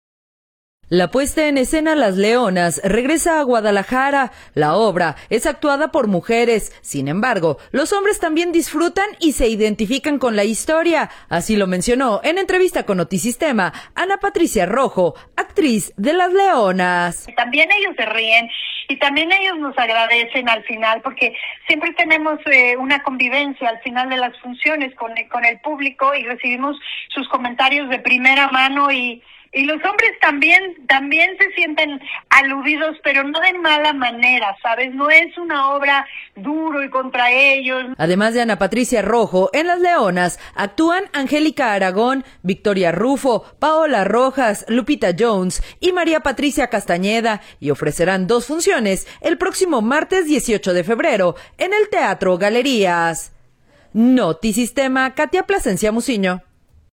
La puesta en escena “Las Leonas” regresa a Guadalajara. La obra es actuada por mujeres, sin embargo, los hombres también disfrutan y se identifican con la historia, así lo mencionó en entrevista con Notisistema, Ana Patricia Rojo, actriz de “Las Leonas”.